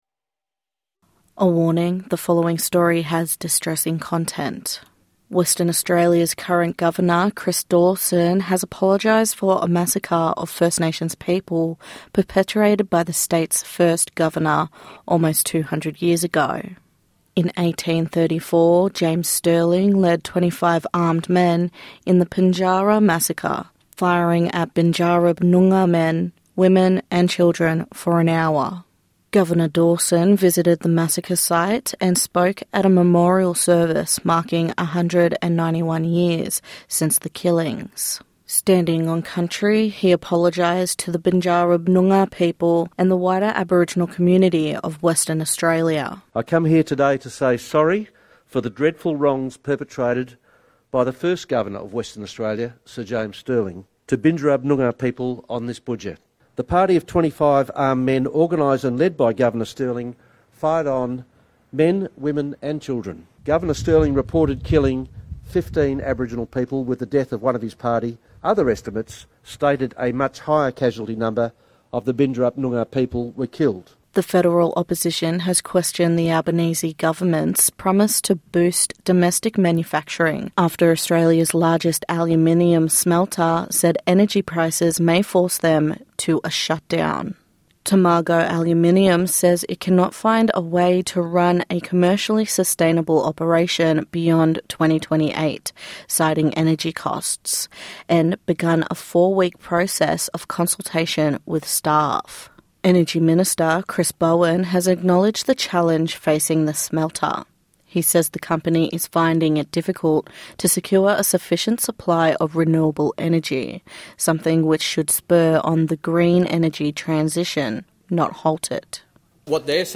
NITV Radio News - 29/10/2025